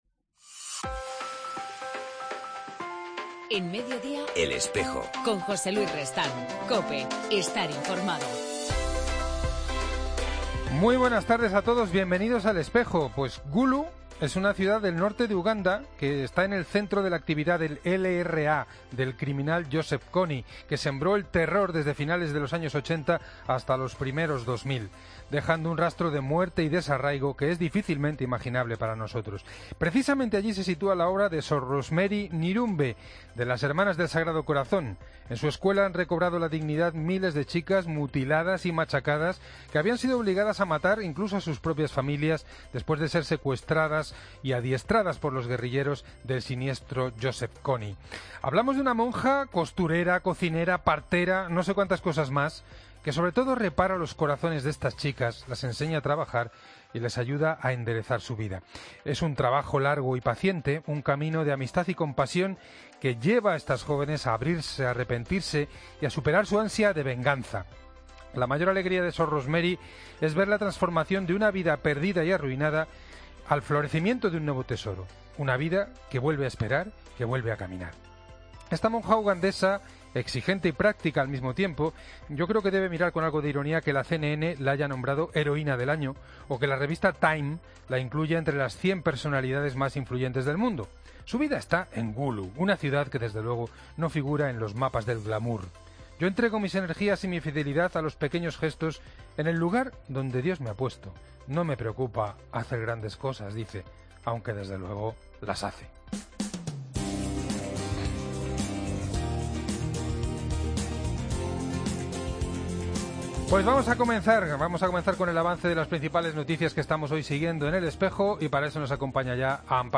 En El Espejo del 22 de septiembre entrevistamos a Carlos Osoro